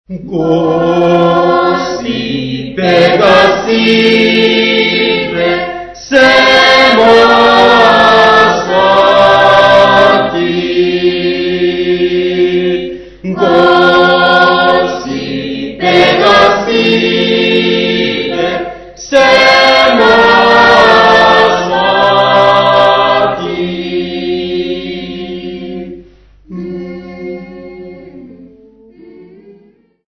Choral music
Field recordings
Africa Eswatini Mzimpofu f-sq
Indigenous music.